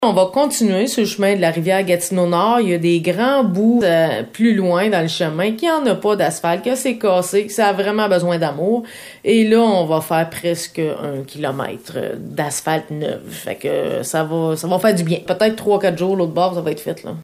La Municipalité de Déléage procède présentement à des travaux de réparations et d’asphaltage sur la rue Dufour et sur le chemin Rivière Gatineau Nord. La mairesse de Déléage parle ici des travaux sur la rue Dufour, effectués lundi.